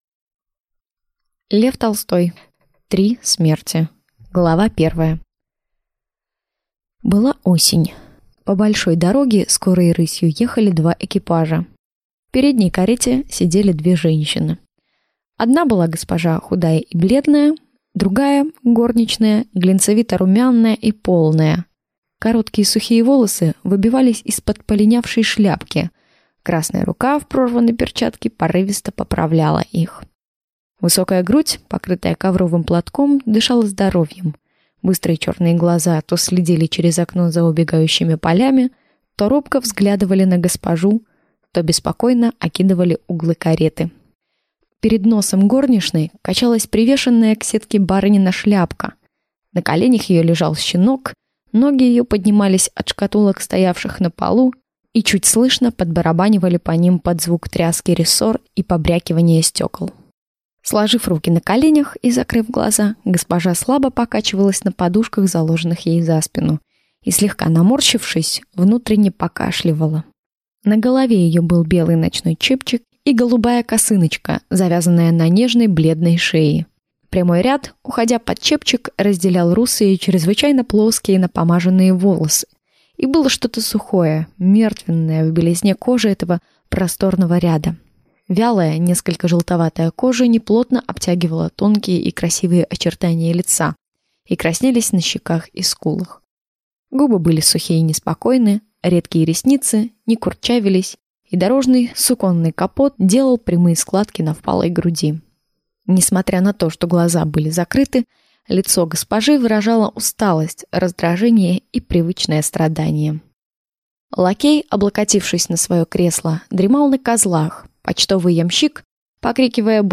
Аудиокнига Три смерти | Библиотека аудиокниг